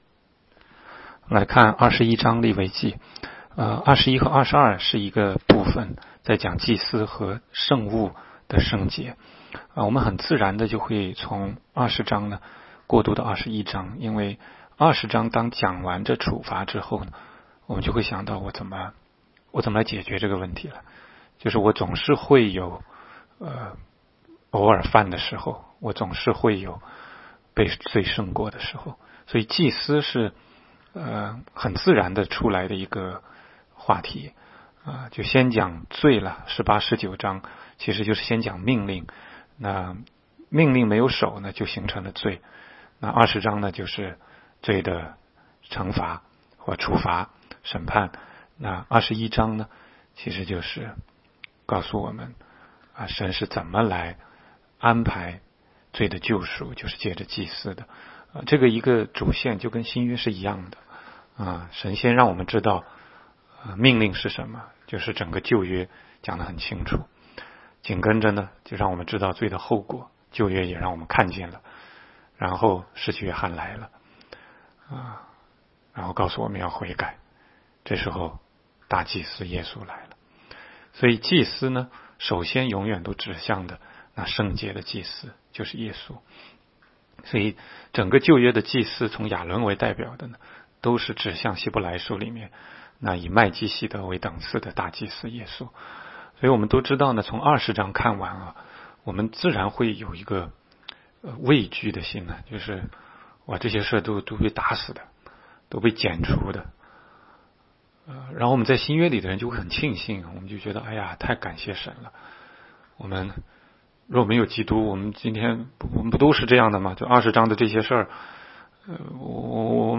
16街讲道录音 - 每日读经-《利未记》21章